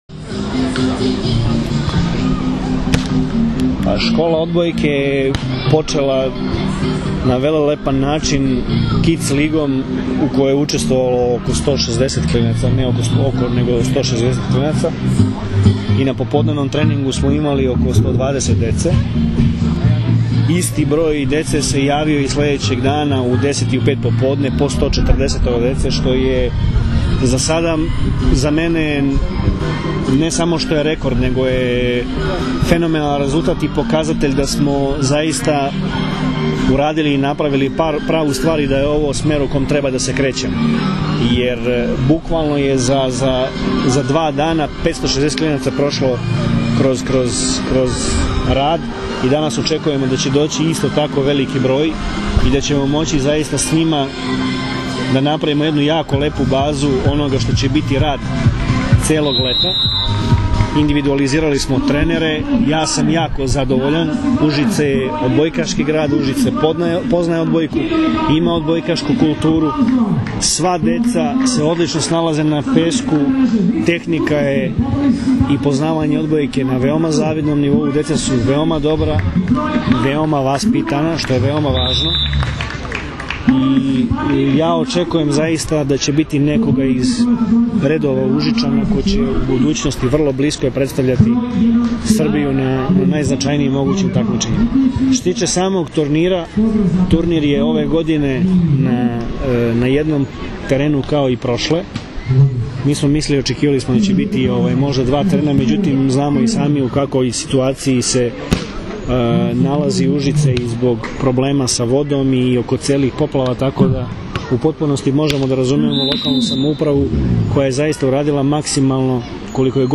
IZJAVA VLADIMIRA GRBIĆA 1